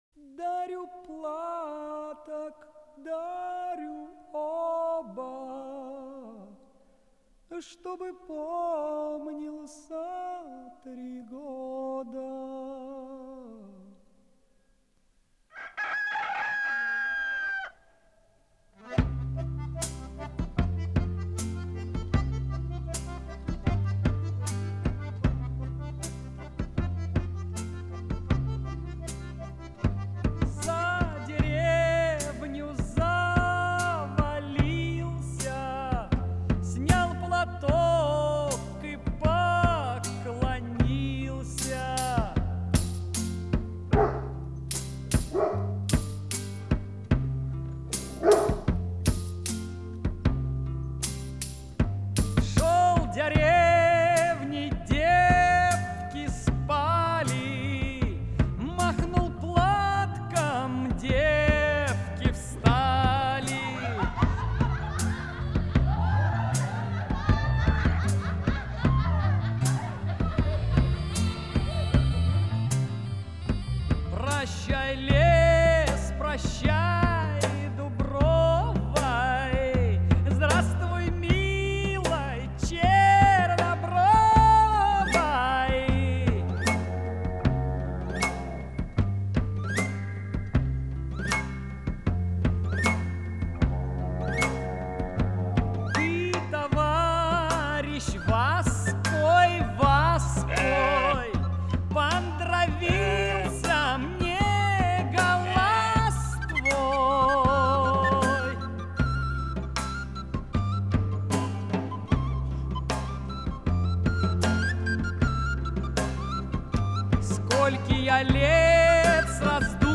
Жанр: Rock, Pop
Формат: CD, Stereo, Album, Compilation
Стиль: Art Rock, Vocal
Сюита на темы народных песен